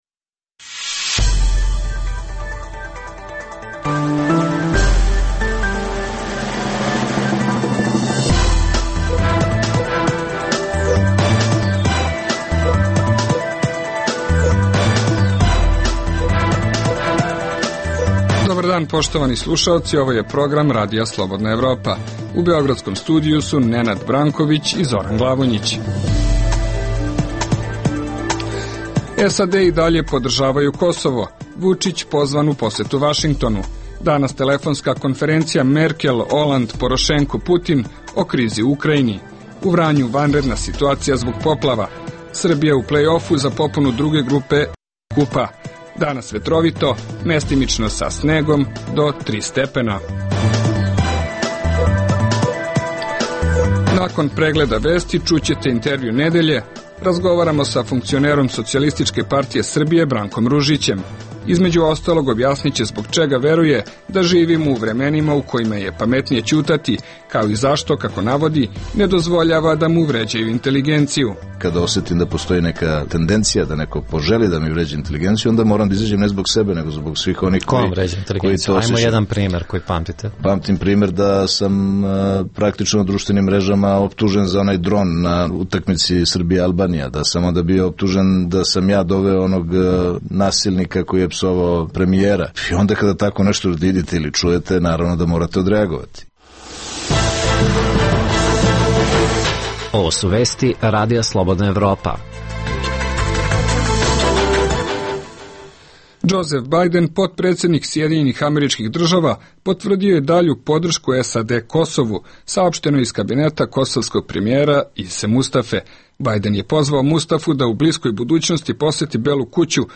Poslušajte najnovije vesti iz Srbije i sveta. Pored toga, čućete i najnoviji Intervju nedelje.